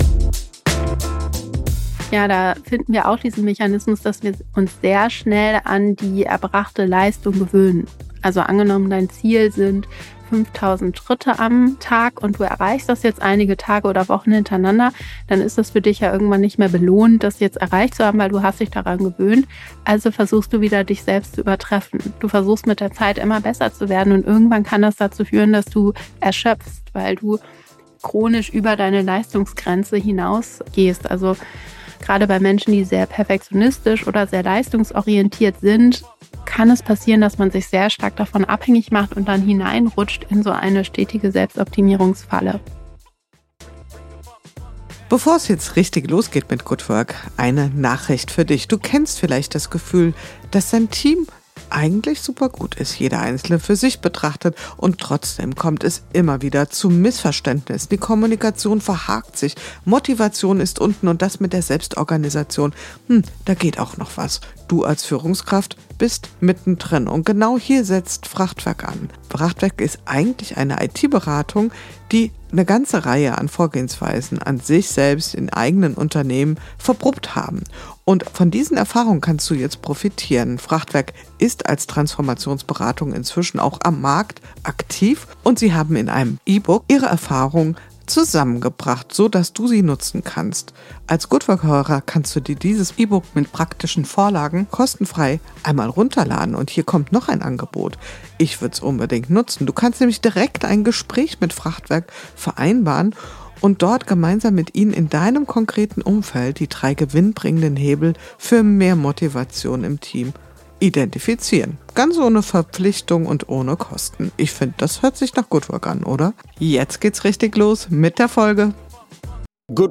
Ein Gespräch über Kontrollverlust, Office Loneliness und den erstaunlich kleinen Hebel, der helfen kann.